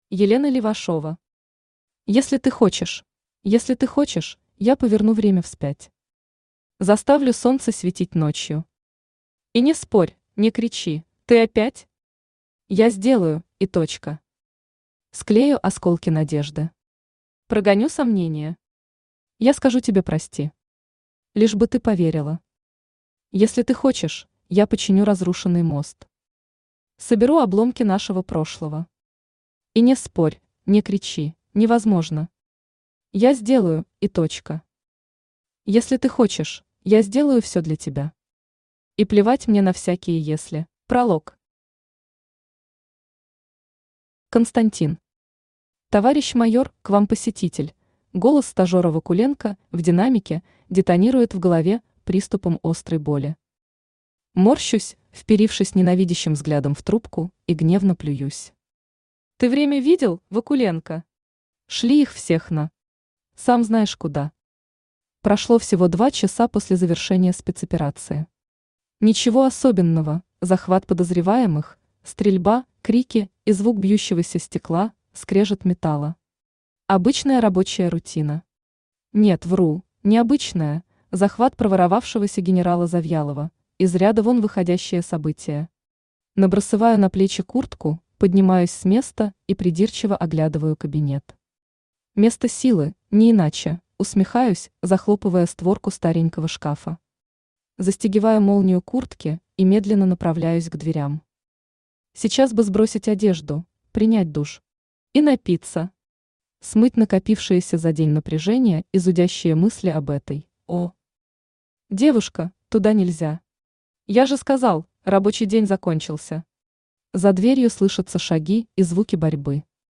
Аудиокнига Если ты хочешь…
Автор Елена Левашова Читает аудиокнигу Авточтец ЛитРес.